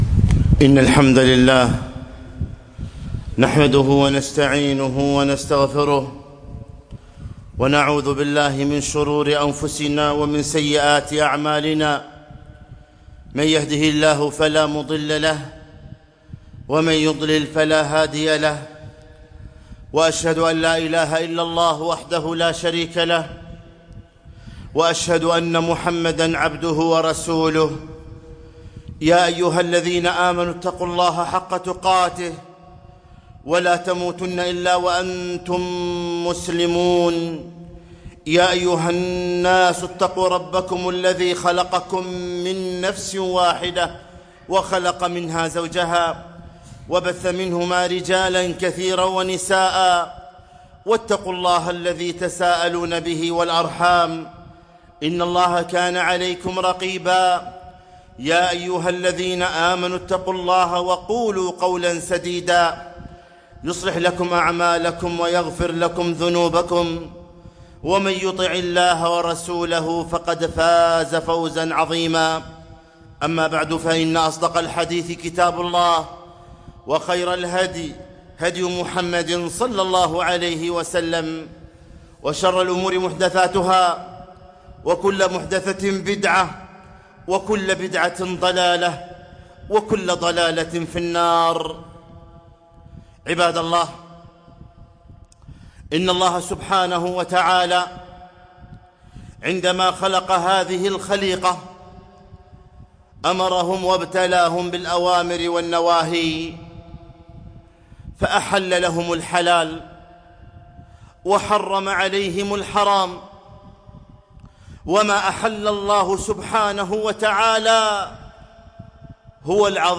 خطبة - اتق المحارم تكن أعبد الناس